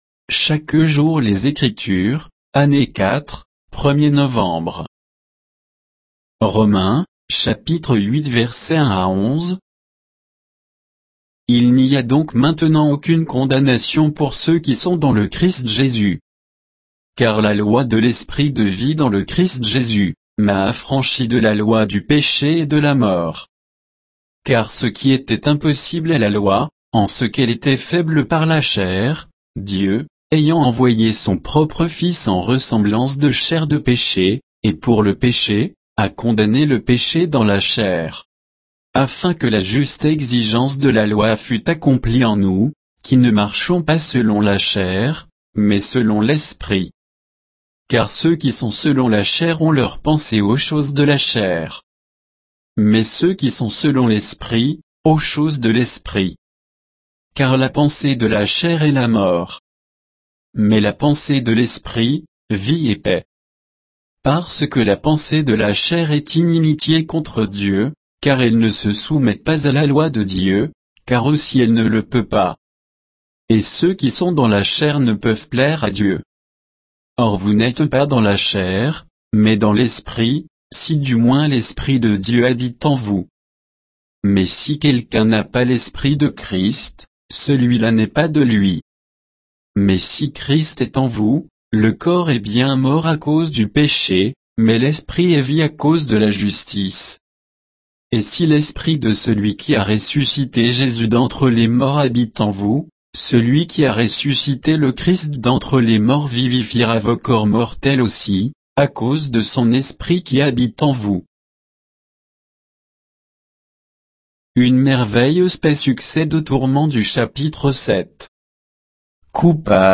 Méditation quoditienne de Chaque jour les Écritures sur Romains 8, 1 à 11